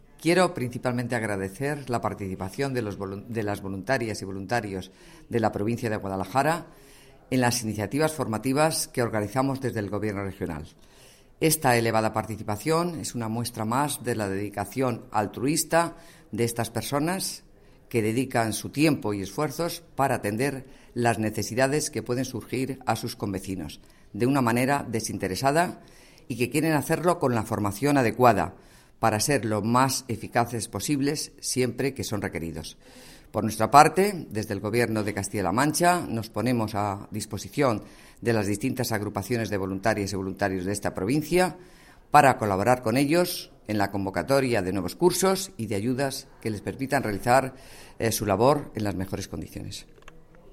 La directora provincial de Hacienda y Administraciones Públicas de la provincia de Guadalajara, Ángela Ámbite, habla de la importancia de las acciones formativas desarrolladas por el Gobierno regional con el voluntariado de Protección Civil.